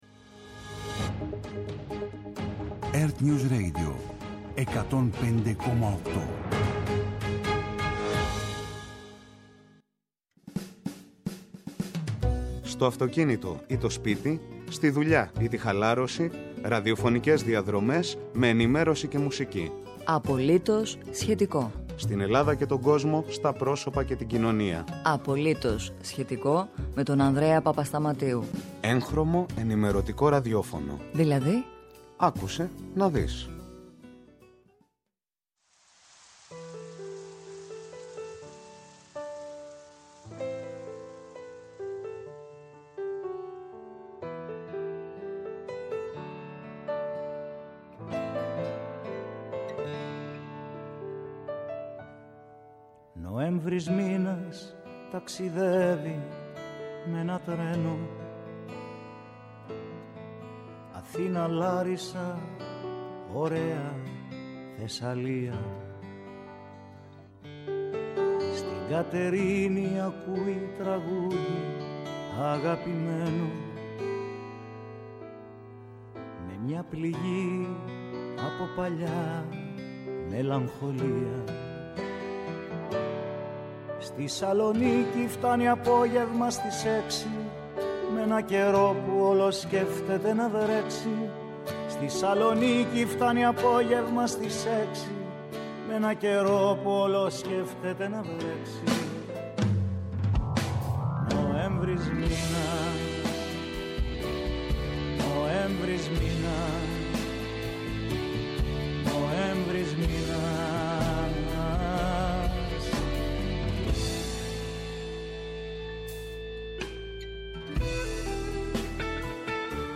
-Σύνδεση με ertnews για την παράδοση των τριών καταζητούμενων αδελφών για το μακελειό στα Βορίζια
ΕΡΤNEWS RADIO